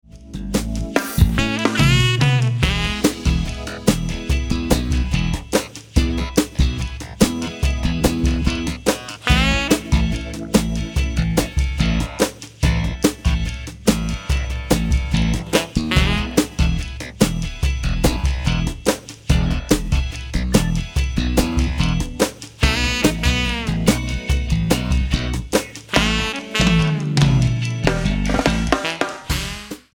72/144 BPM